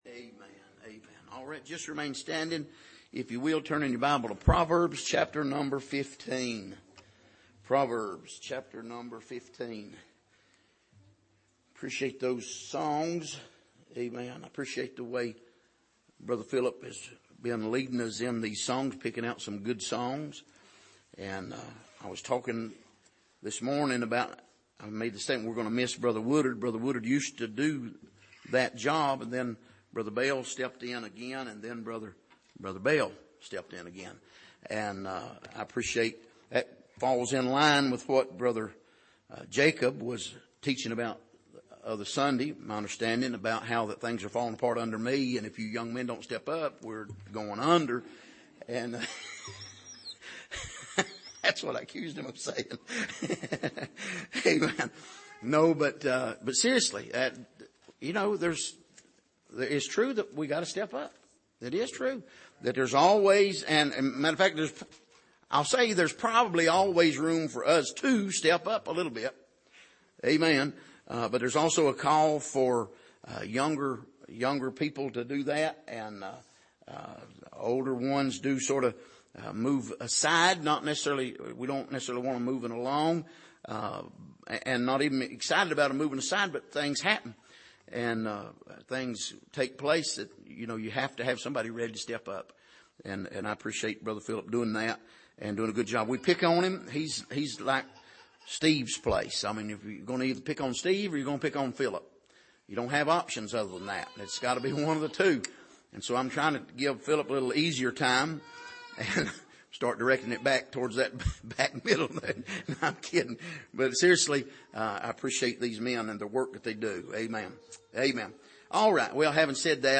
Passage: Proverbs 15:10-17 Service: Sunday Evening